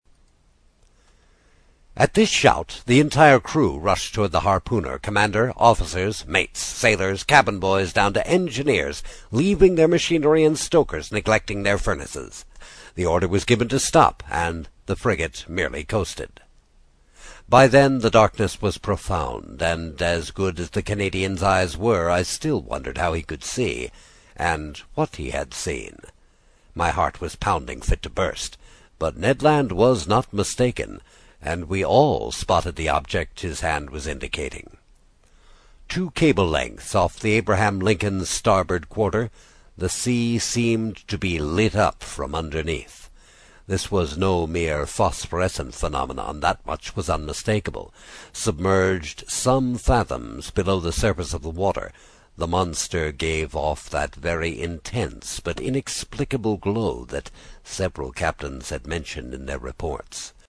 英语听书《海底两万里》第59期 第6章 开足马力(1) 听力文件下载—在线英语听力室